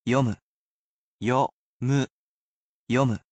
You can repeat after the word pronunciation, but the sentences are at regular speed in order to acclimate those learning to the pace.